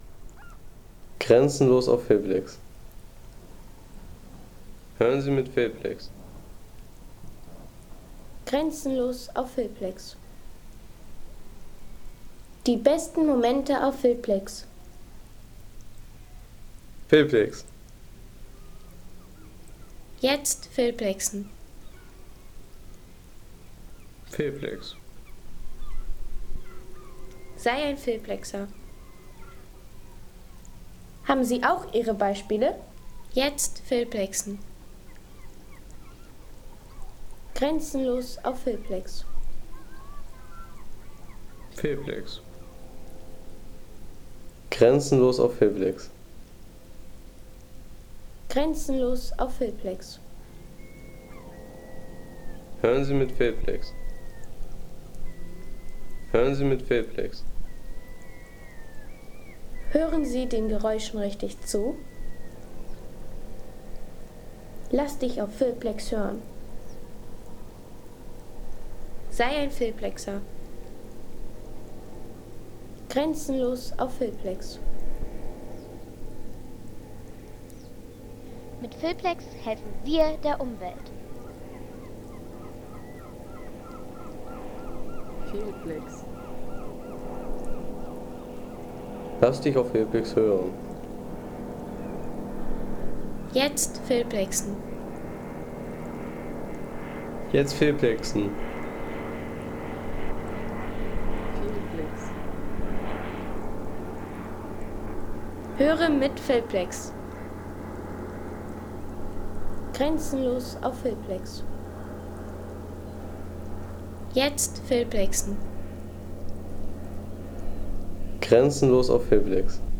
Wattenmeer Strand
Erholungstag am Strand in Dagebüll.